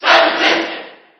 File:Samus Cheer JP SSB4.ogg